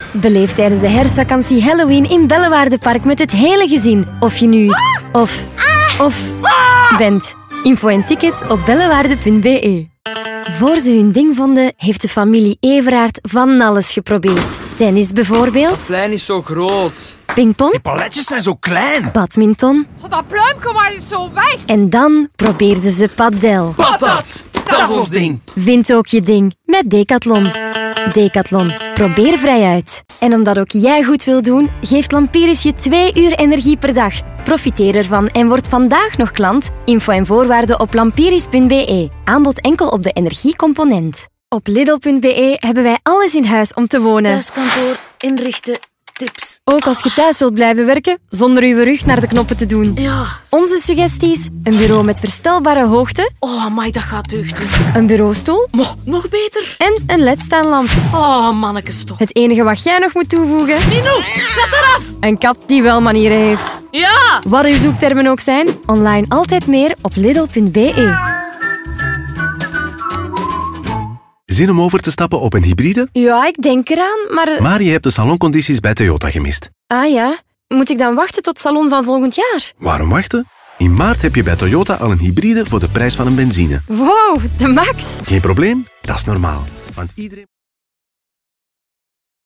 Commercial, Young, Natural, Versatile, Friendly
Commercial